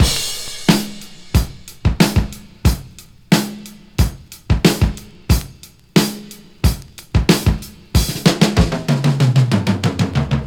Free drum groove - kick tuned to the E note. Loudest frequency: 2046Hz
• 92 Bpm Drum Beat E Key.wav
92-bpm-drum-beat-e-key-Y1o.wav